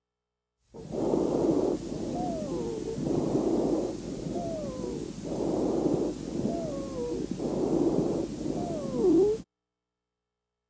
Wheezing is a high-pitched whistling sound heard during exhalation and occasionally during inspiration.
Wheezing voice file
expiratory-wheezes-adult-_-peds-lung-sounds.wav